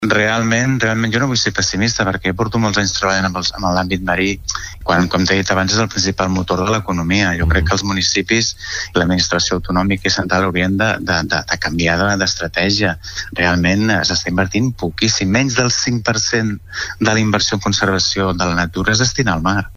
Entrevistes SupermatíNotíciesSant Feliu de GuíxolsSupermatí